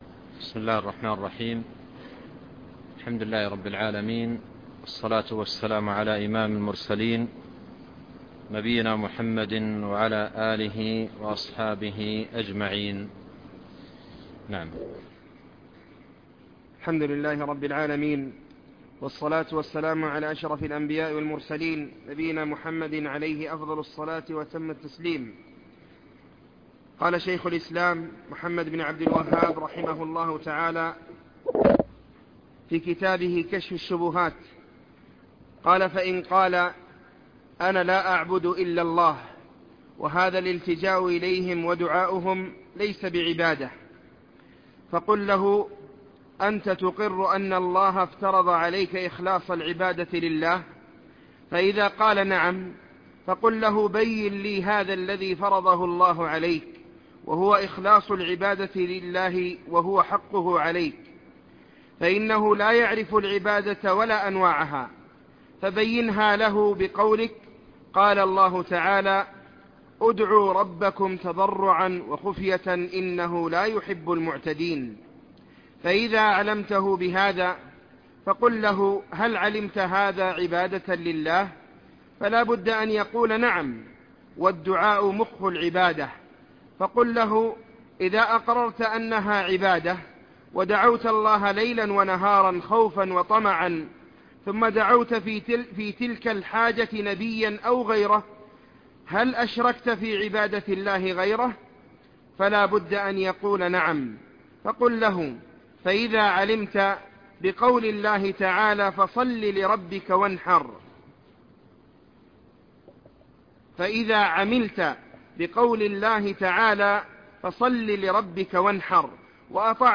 الدرس 2